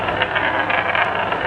rolling_hard.wav